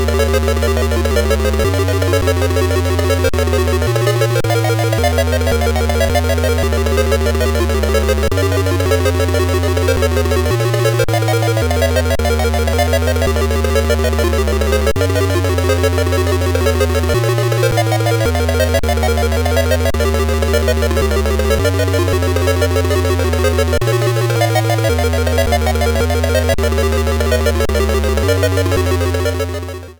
musical theme
much choppier version